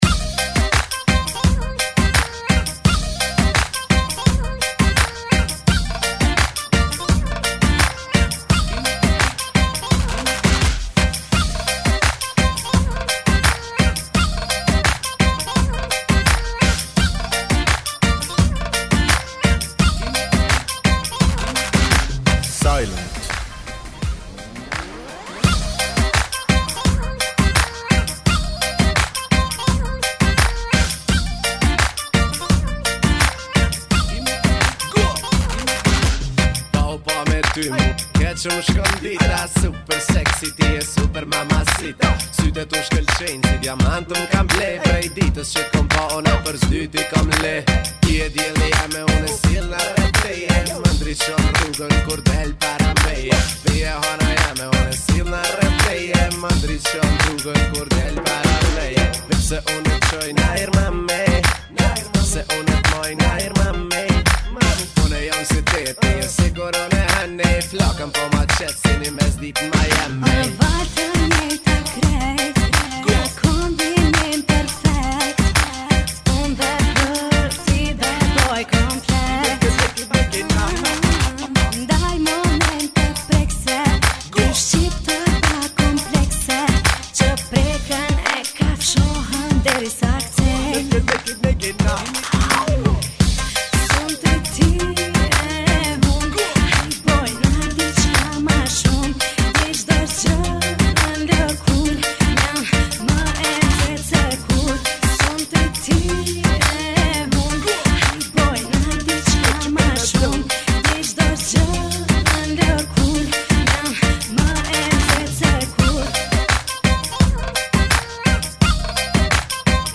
[ Bpm 85 ]